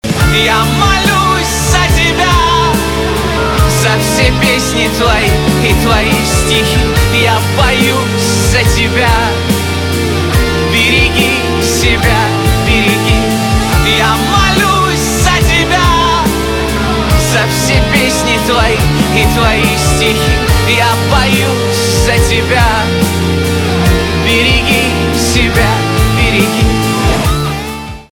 русский рок
пианино , барабаны , гитара , чувственные